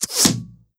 Arrow Impact.wav